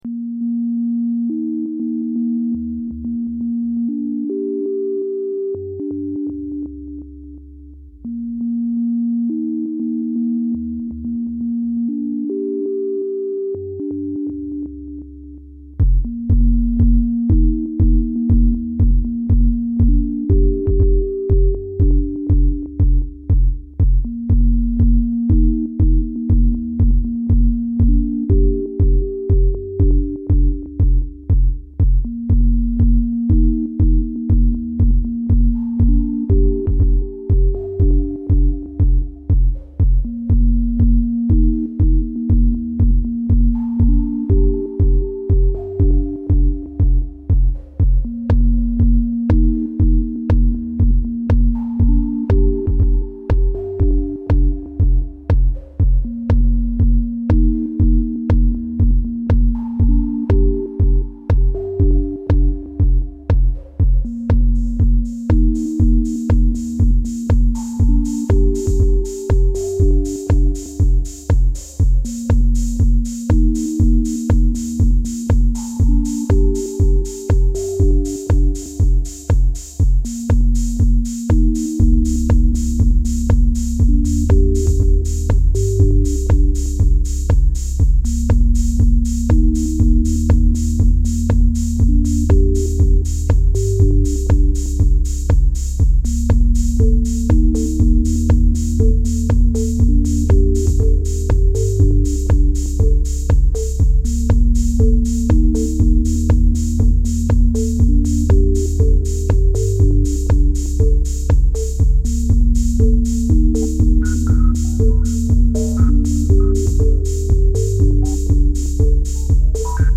Atm i’m challenged to make jams with only the (new) analog machines.
(added a little de-mudding after recording)